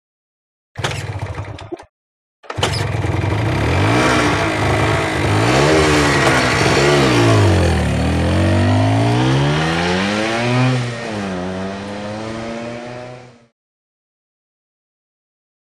Motorcycle; Start / Away; Moped 50 Cc Start And Away Tr07